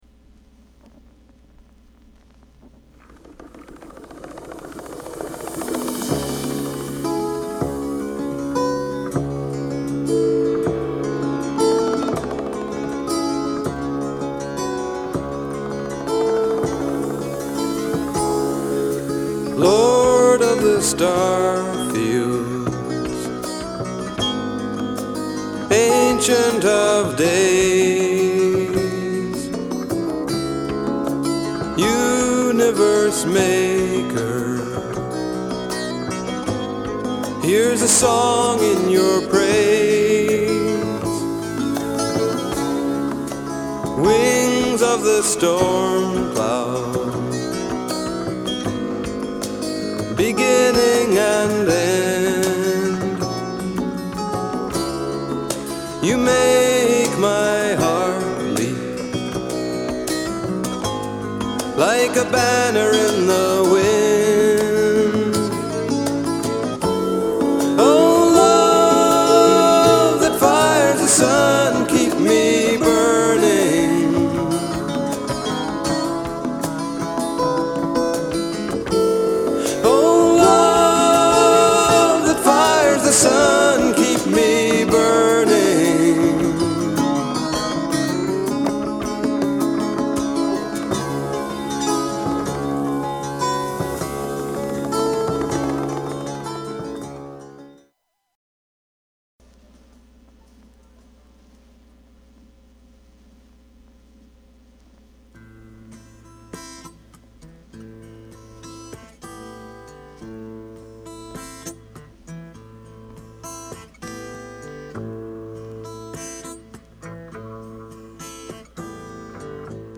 ROCK / BLUES ROCK
ジャズやブルースを基礎としながらも、本作ではアフリカのリズムを取り入れるなど新しいクリエーションを展開。